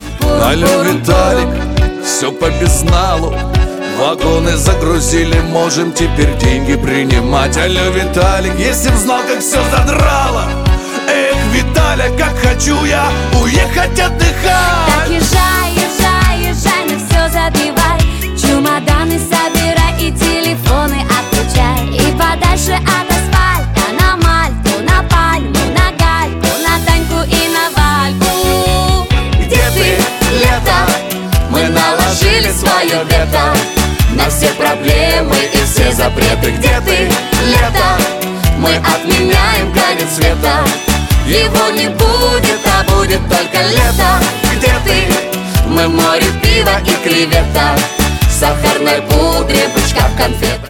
• Качество: 128, Stereo
поп
громкие
красивый женский голос